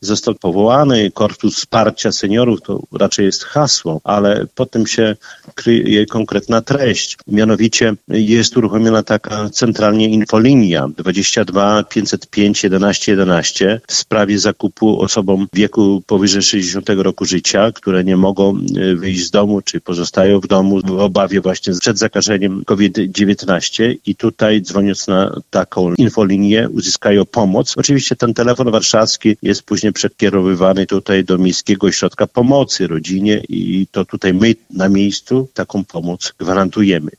O szczegółach mówi Czesław Renkiewicz, prezydent Suwałk.